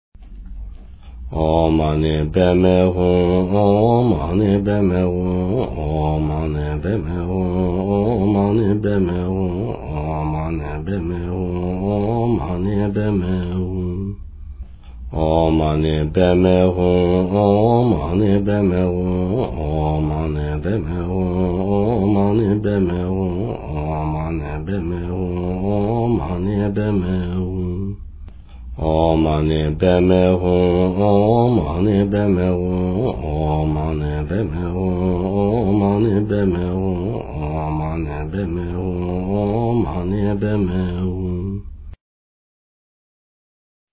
Om Mani Padme Hum - Kyabje Lama Zopa Rinpoche - fast
Om Mani Padme Hum - Kyabje Lama Zopa Rinpoche - fast.mp3